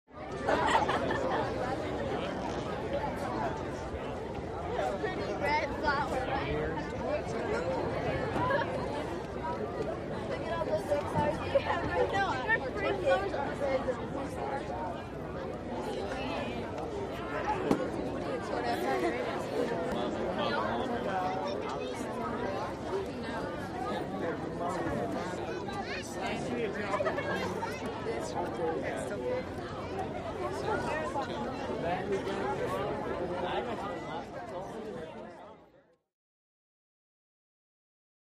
Picnic Walla | Sneak On The Lot
Medium Crowd Mixed Lively Walla With Men, Women, And Children